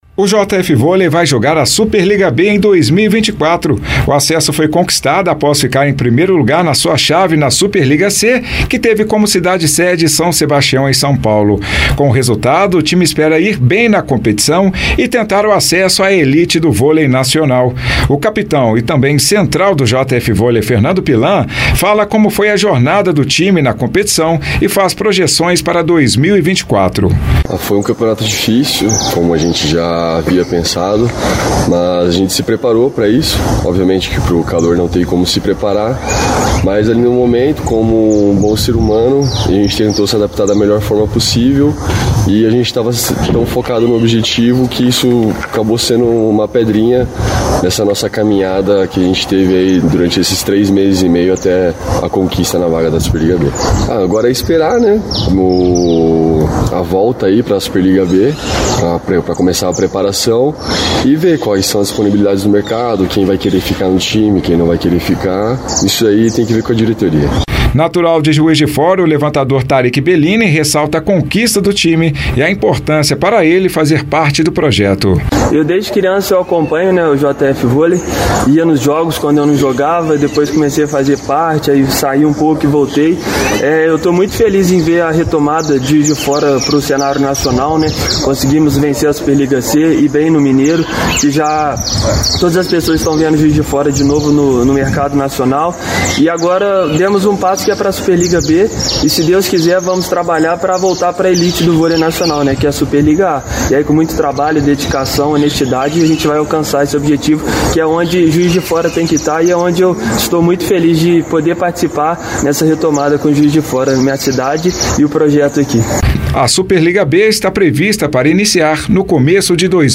Foco, confiança e emoção: jogadores falam sobre campanha invicta e futuro do JF Vôlei